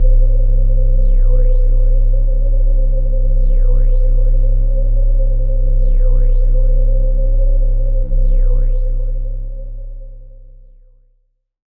Index of /90_sSampleCDs/Club_Techno/Atmos
Atmos_11_C1.wav